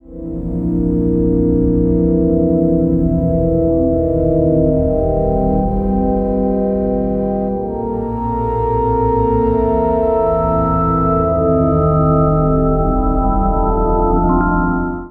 Track 10 - Underwater Pad.wav